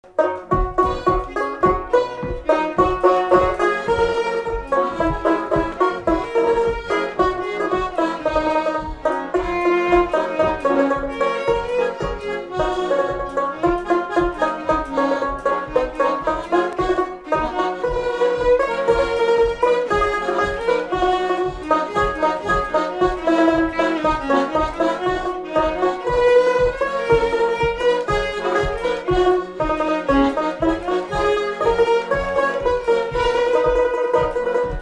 Instrumental
danse : fox-trot
Pièce musicale inédite